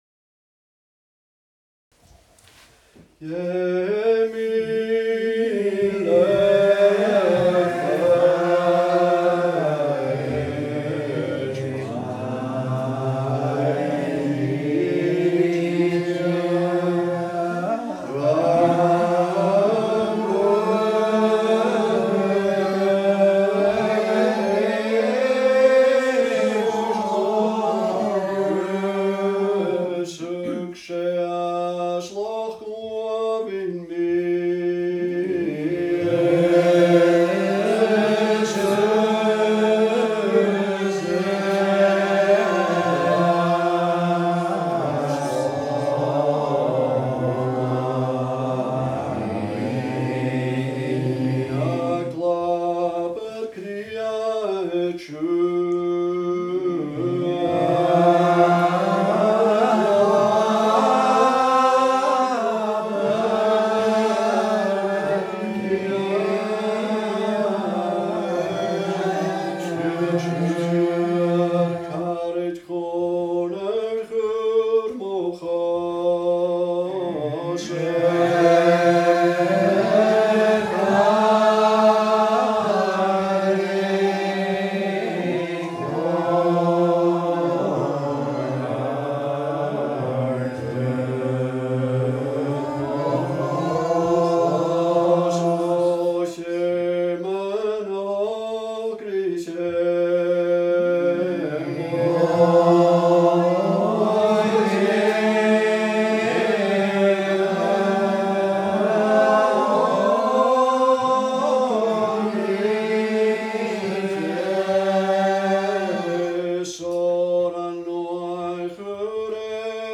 Gaelic Psalmody - class 5
A’ Seinn nan Sailm Gaelic Psalmody